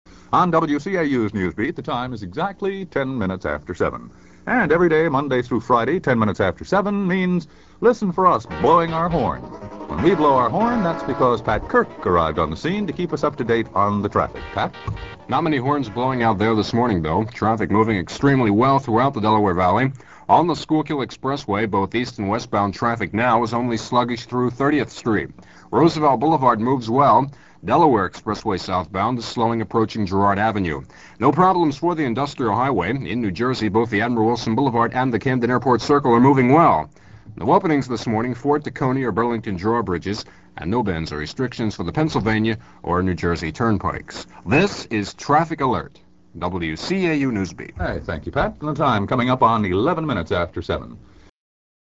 The following clips are from an October 15, 1970 aircheck of WCAU-AM's Morning NewsBeat program, which was all news from 6 to 9 a.m.